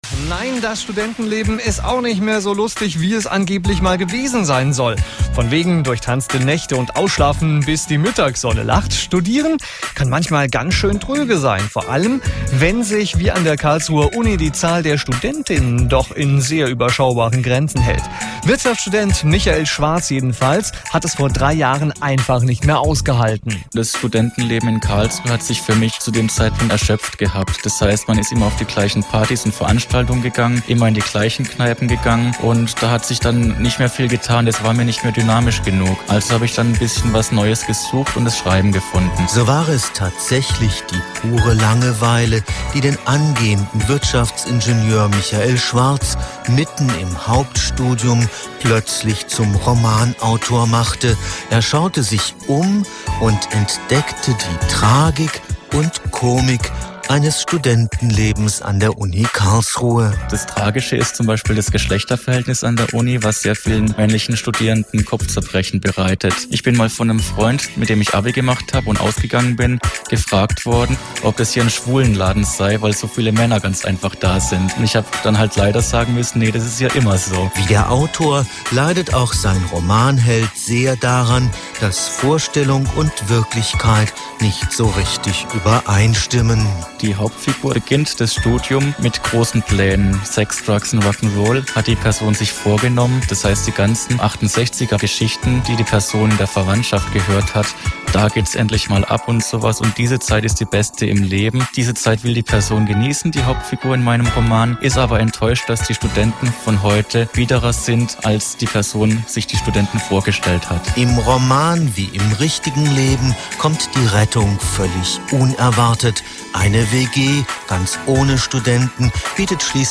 Interviewter